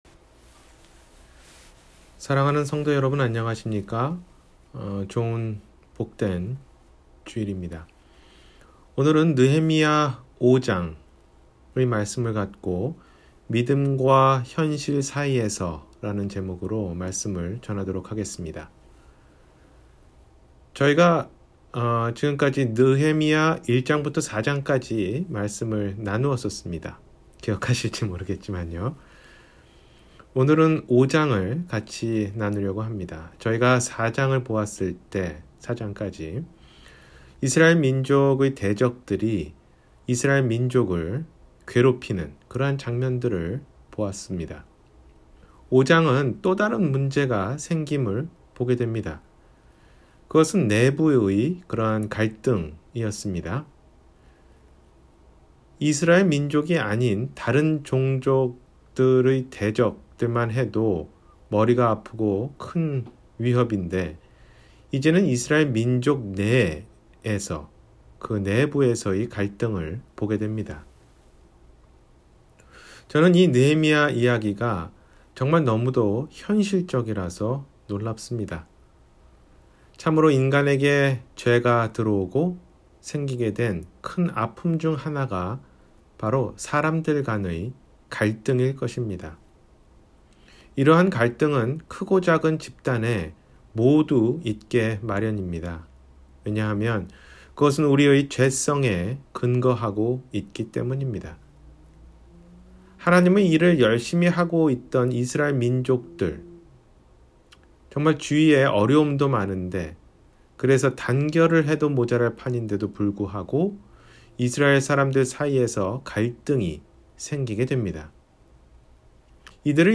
믿음과 현실 사이에서 – 주일설교 – 갈보리사랑침례교회